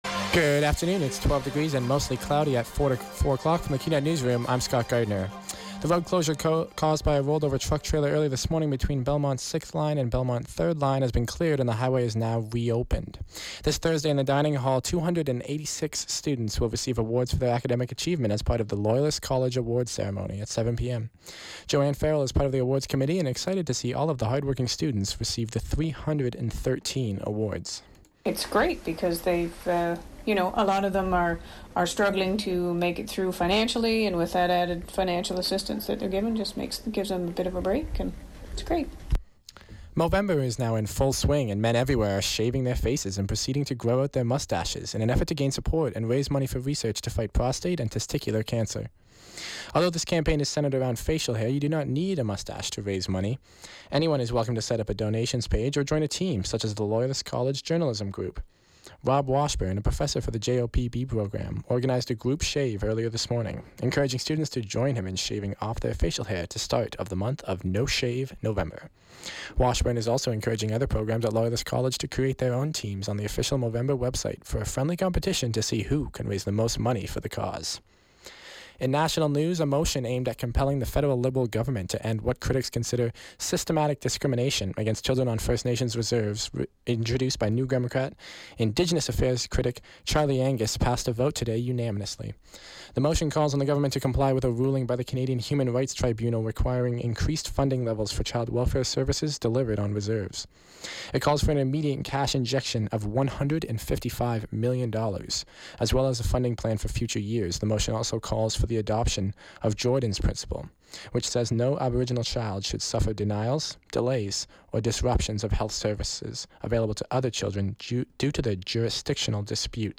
91X FM Newscast – Tuesday, Nov. 1, 2016, 4 p.m.